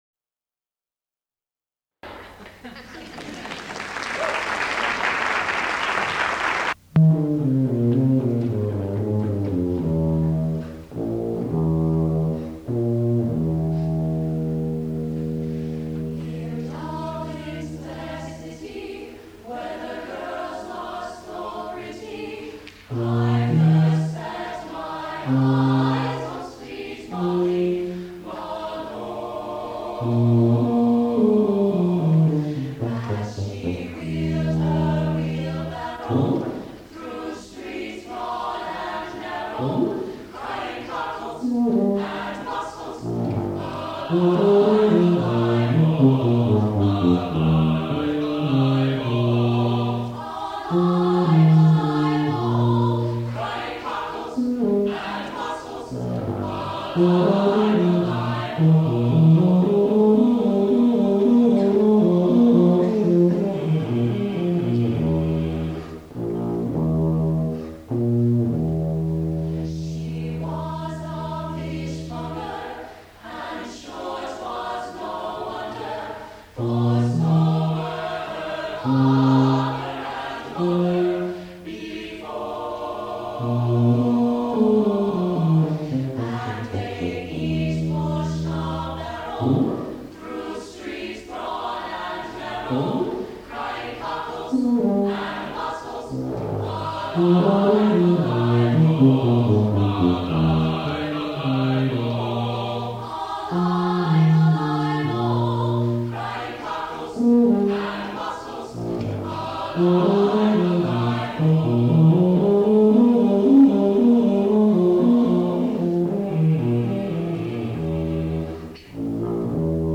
for SATB Chorus and Tuba (1998)